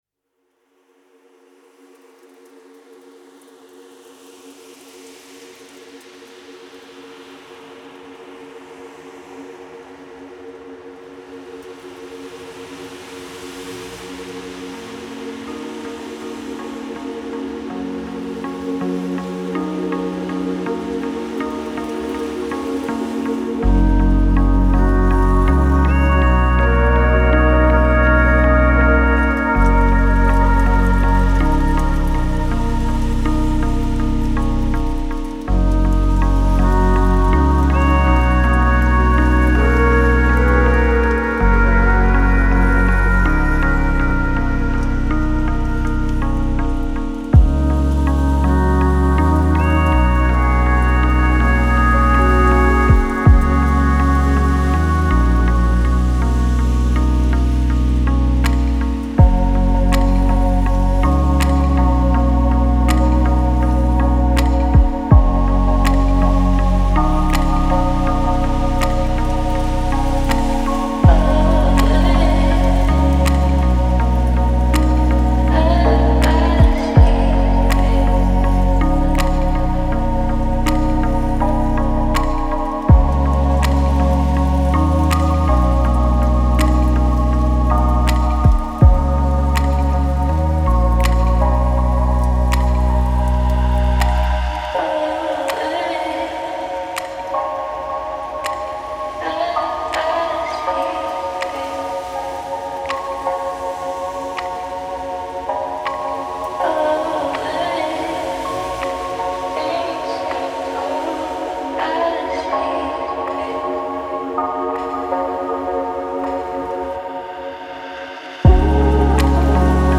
Drift through tranquil and warm synth landscapes.